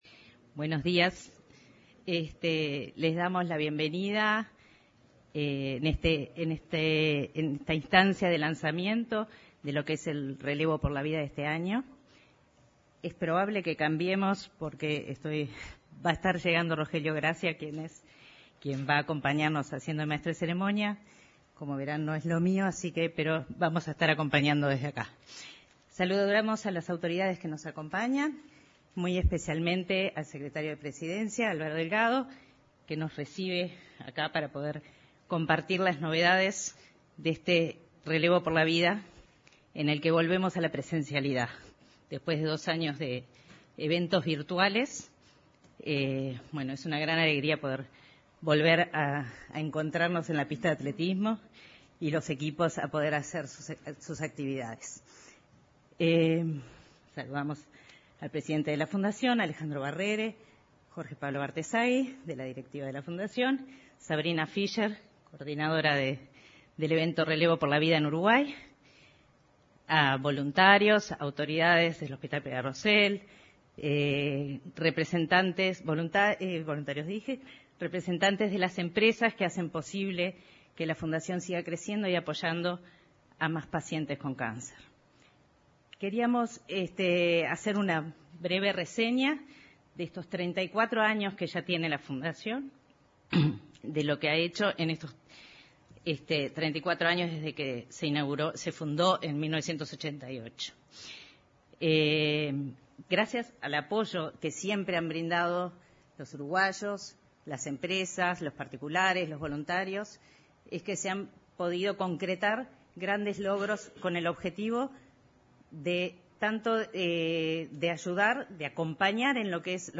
Este 17 de agosto, se realizó el lanzamiento del evento Relevo por la Vida, de la Fundación Peluffo Giguens.